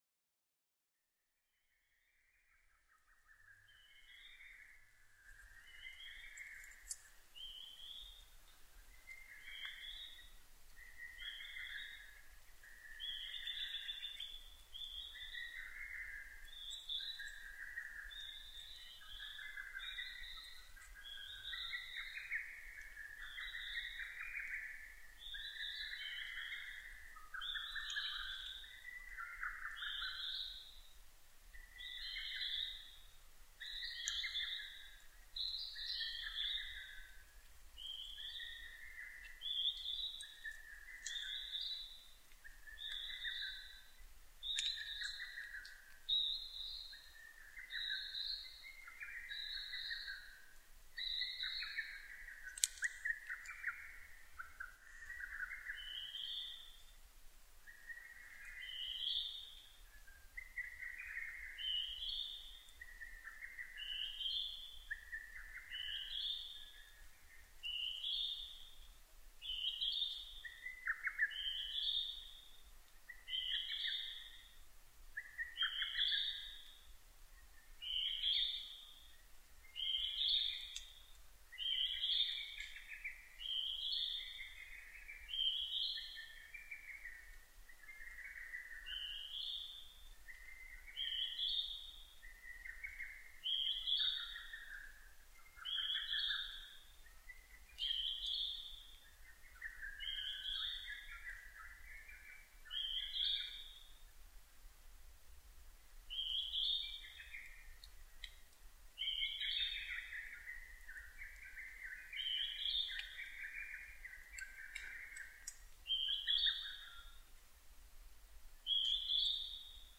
Mic: Panasonic WM-61A  Binaural Souce with Dummy Head